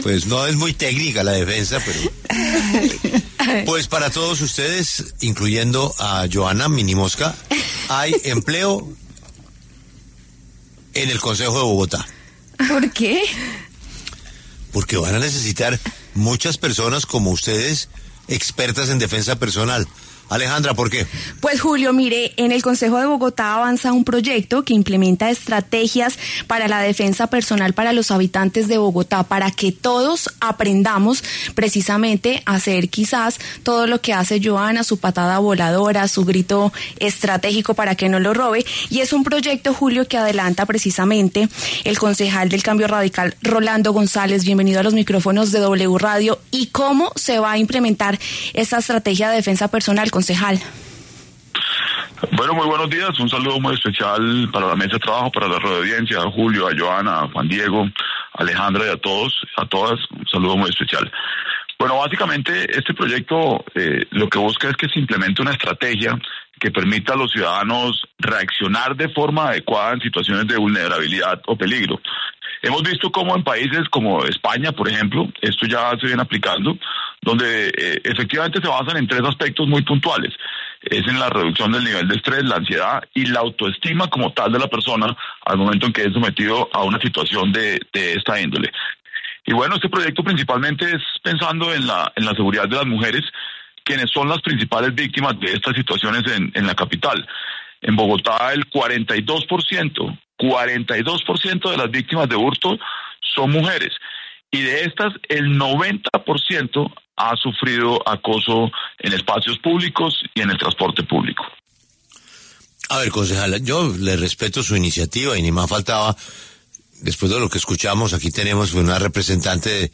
“Este proyecto lo que busca es implementar una estrategia que permita a los ciudadanos reaccionar de forma adecuada en momentos de vulnerabilidad y peligro. En España incluso se ha aplicado, basándose en la reducción del estrés, la ansiedad y el aumento de la autoestima cuando las personas se enfrentan a este tipo de situaciones. También busca la protección de las mujeres; el 42% de las víctimas de hurto son mujeres”, dijo González en los micrófonos de W Radio.